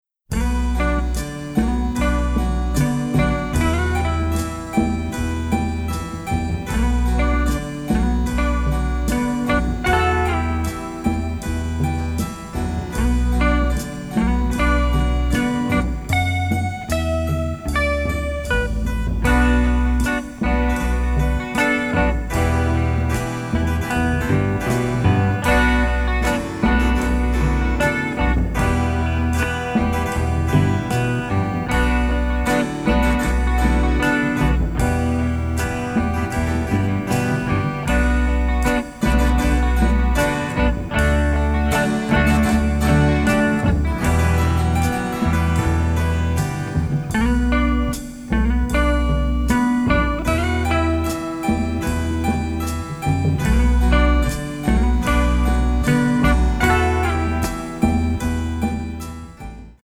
Italian masterpiece of psychedelia!